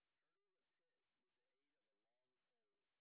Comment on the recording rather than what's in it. sp09_white_snr20.wav